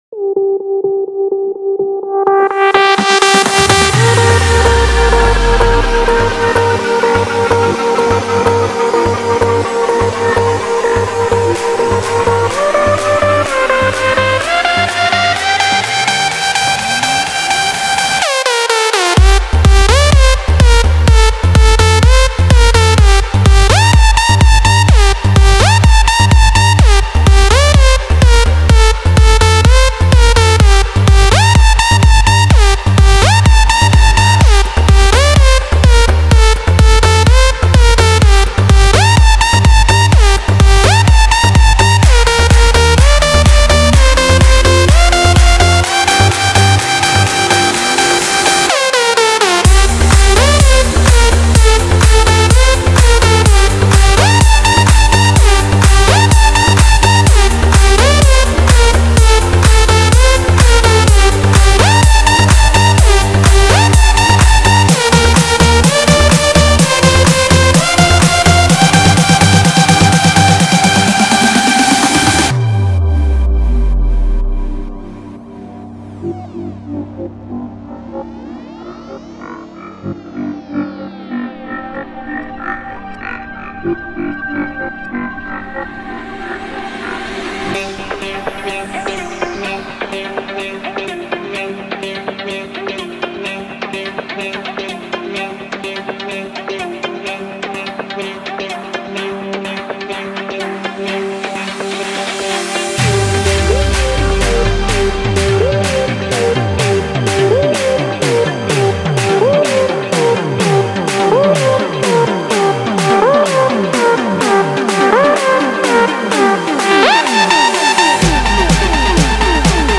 Electro House